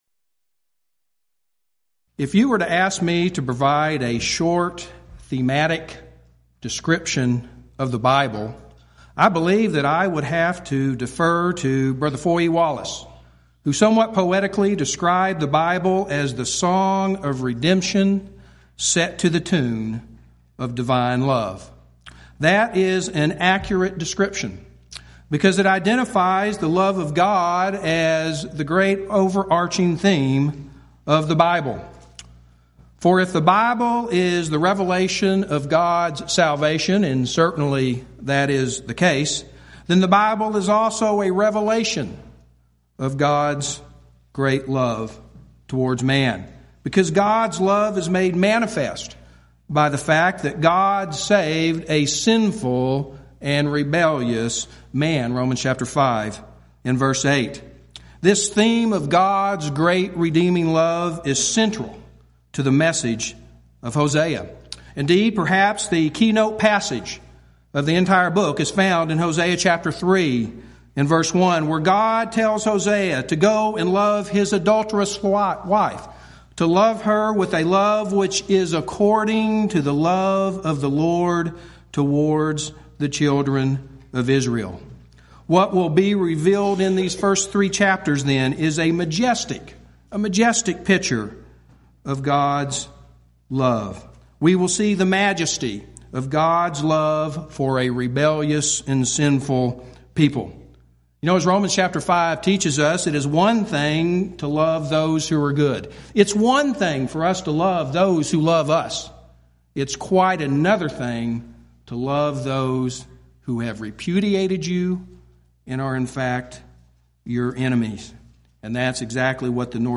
Event: 12th Annual Schertz Lectures Theme/Title: Studies in the Minor Prophets
lecture